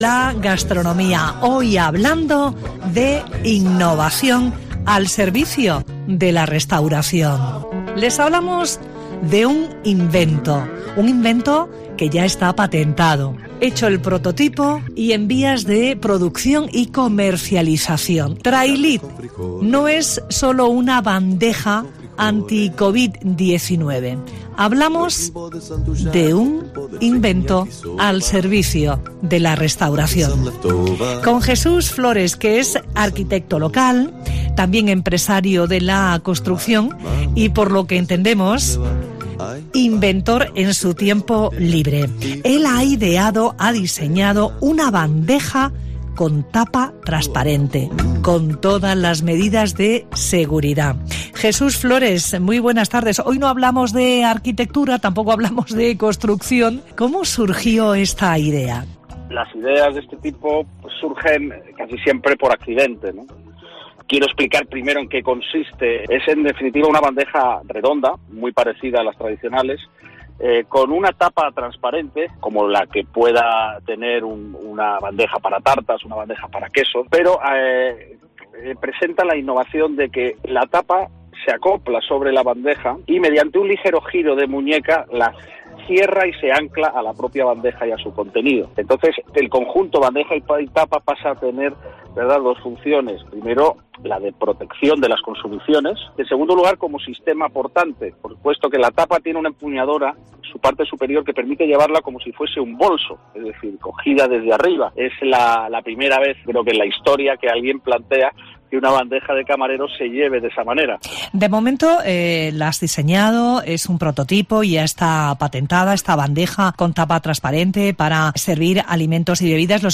Entrevista en Onda Cero